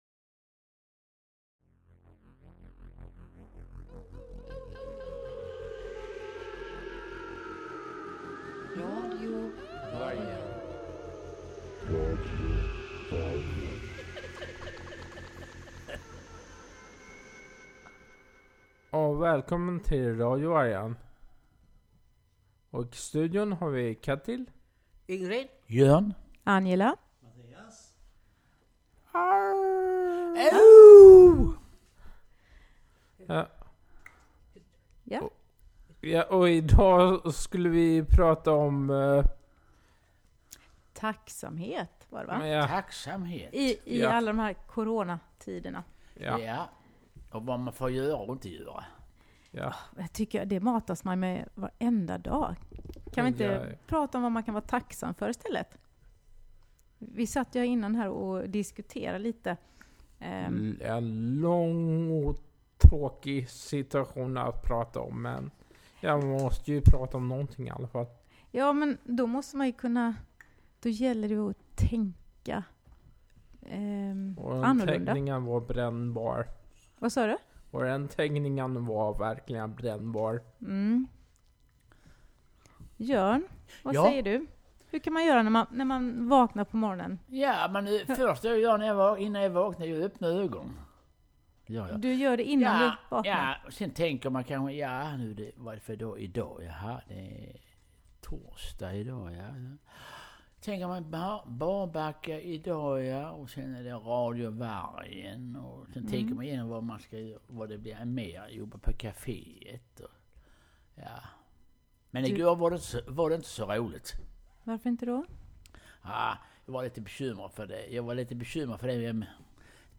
Idag har vi en vikarie i studion.